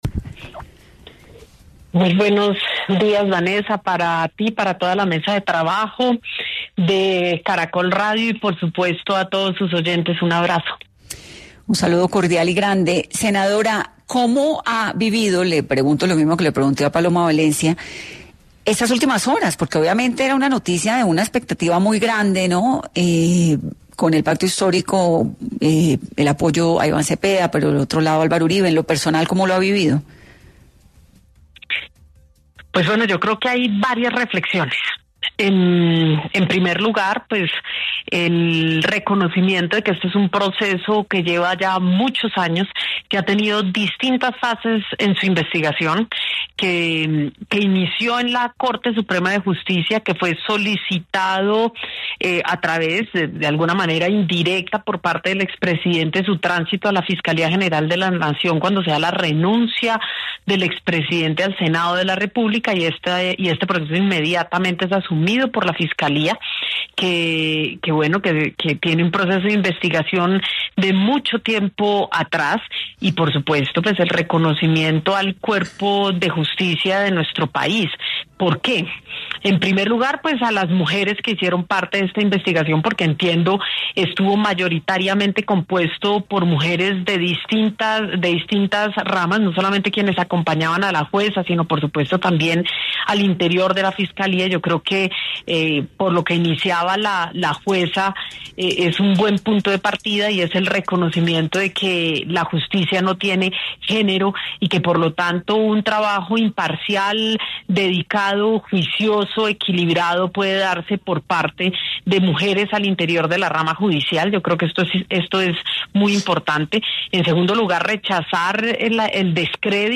En 10AM estuvo la senadora y precandidata del Pacto Histórico, quién habló sobre su candidatura y el posible lanzamiento de Cepeda a las campañas presidenciales.